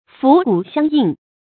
桴鼓相应 fú gǔ xiāng yìng
桴鼓相应发音
成语注音ㄈㄨˊ ㄍㄨˇ ㄒㄧㄤ ㄧㄥˋ